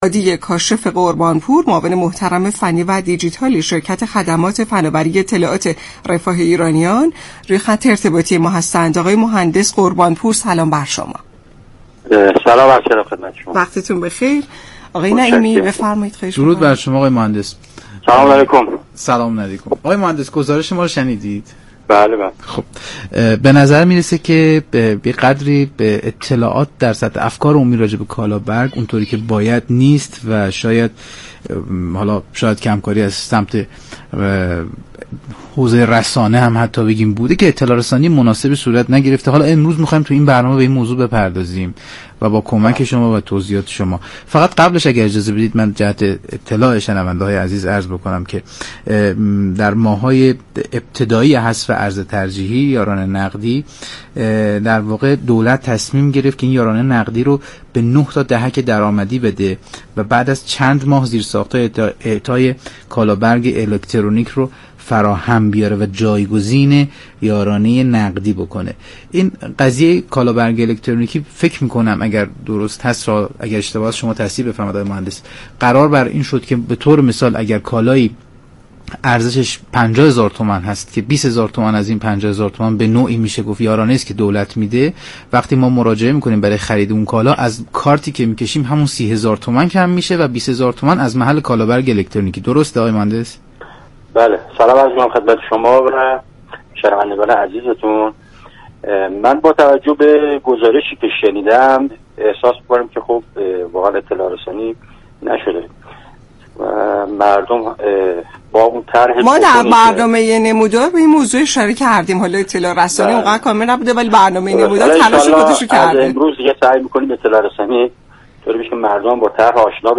برنامه «نمودار» شنبه تا چهارشنبه هر هفته ساعت 10:20 از رادیو ایران پخش می شود.